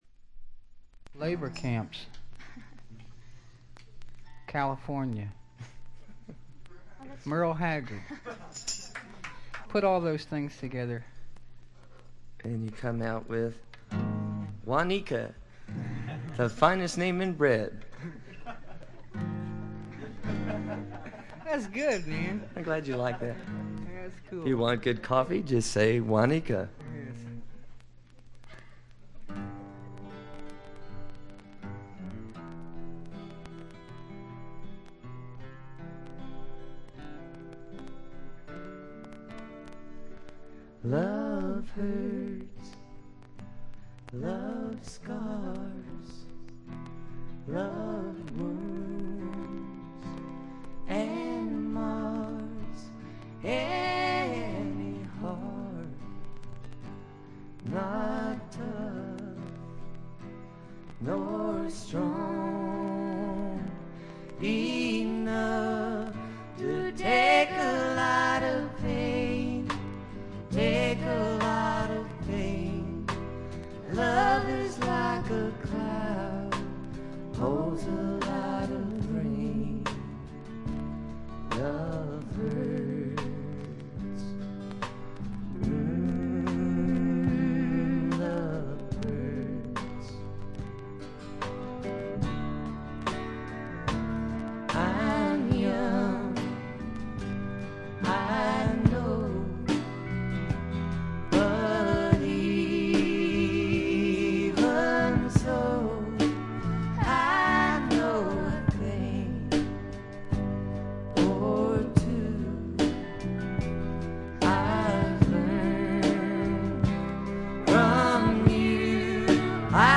ホーム > レコード：カントリーロック
ほとんどノイズ感無し。
試聴曲は現品からの取り込み音源です。